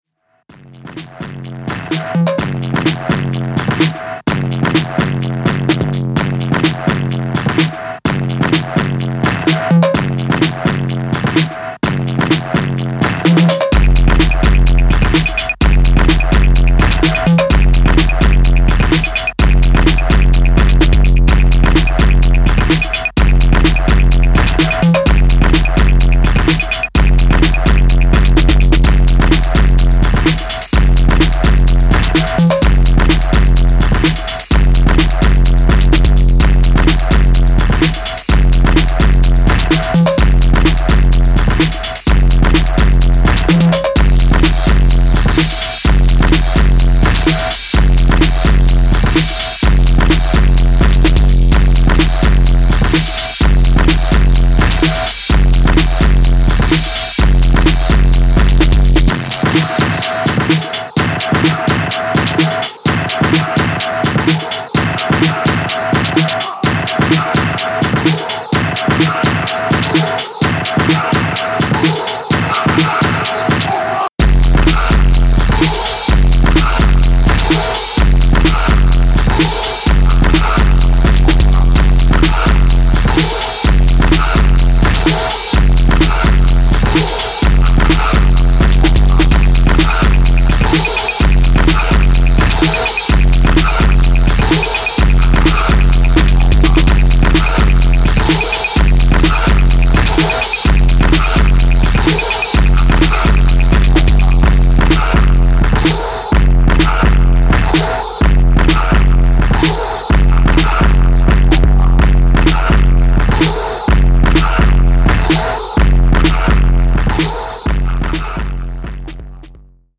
Filed under: Electro / Techno